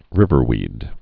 (rĭvər-wēd)